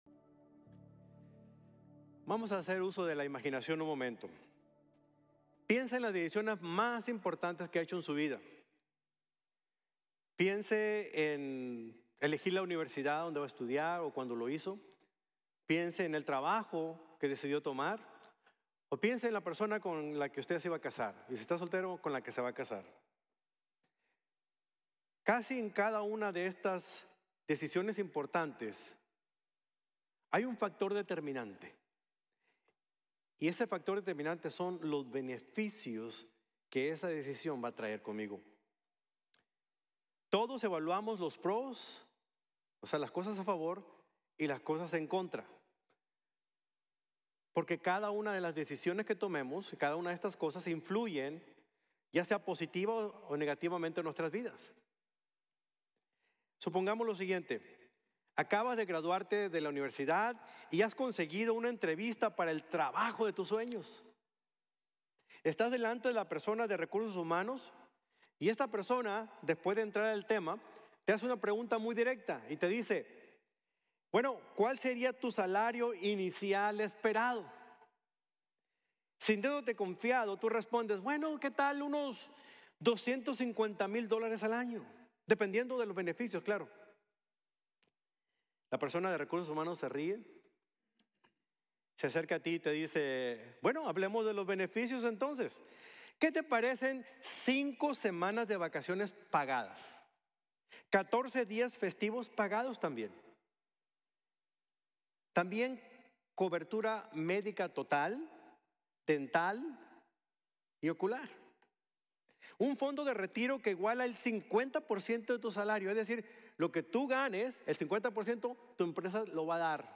Nuestro Paquete de Beneficios | Sermon | Grace Bible Church